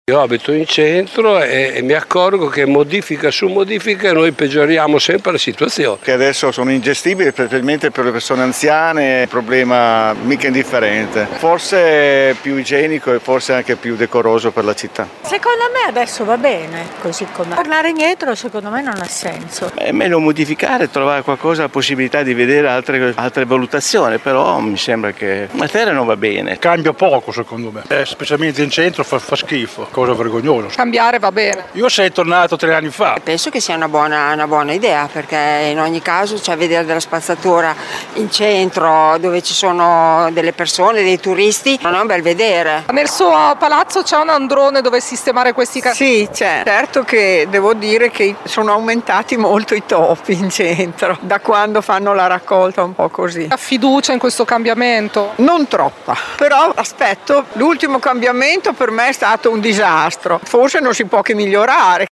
Cosa ne pensano i residenti del centro di questo dietrofront?
VOX-RACCOLTA-CENTRO.mp3